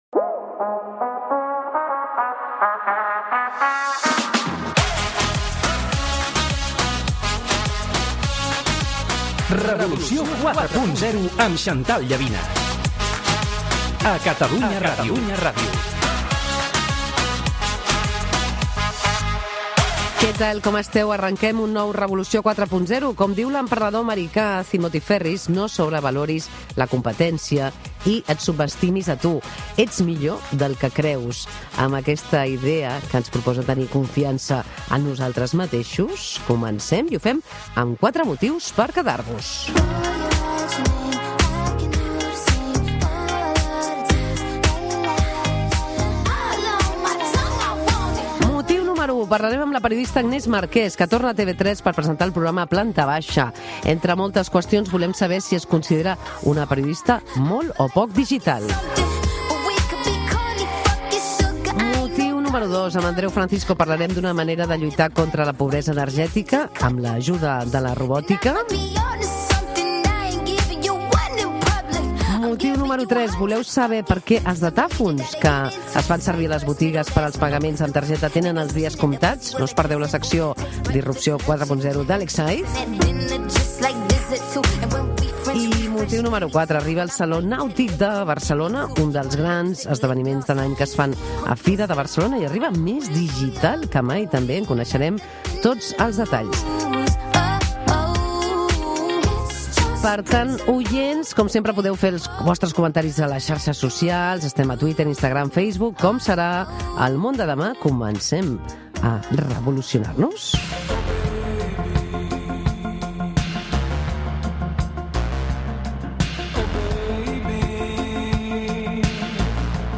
b65cc447efa9af0b93e062df5773ce2cc040701c.mp3 Títol Catalunya Ràdio Emissora Catalunya Ràdio Cadena Catalunya Ràdio Titularitat Pública nacional Nom programa Revolució 4.0 Descripció Programa 302. Careta del programa, salutació, cita sobre emprenedoria, sumari de continguts, entrevista a la periodista i presentadora Agnès Marquès.